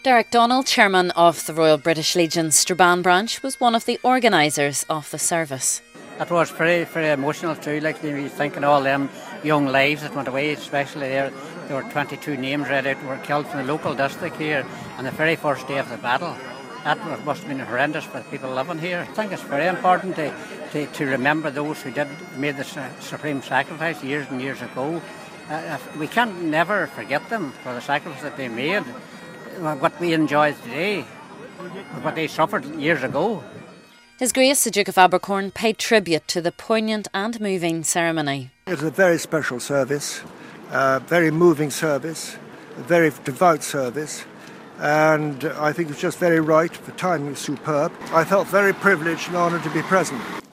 Several hundred people attended a service in Strabane yesterday...a Service of Commemoration and Remembrance for the Centenary of the Battle of The Somme. The service at Strabane Presbyterian Church was attended by the Fine Gael Minister Heather Humphreys.